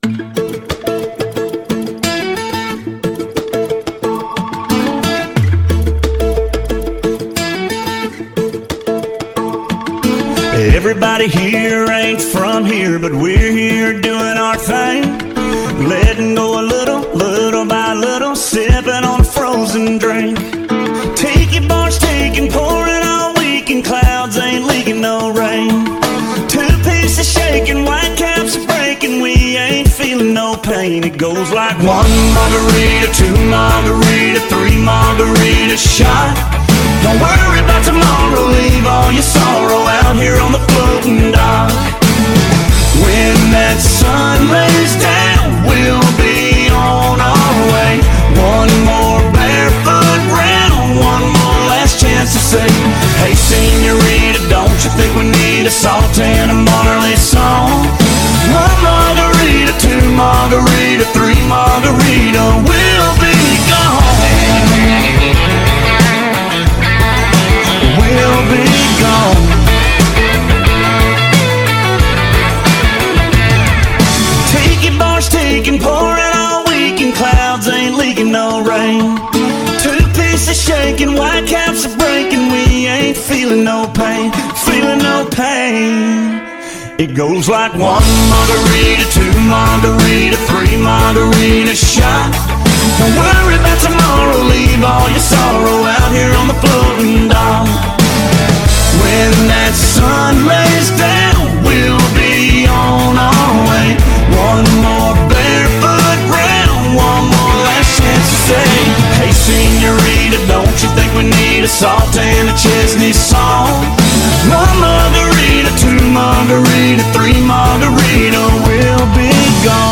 BPM180
Audio QualityMusic Cut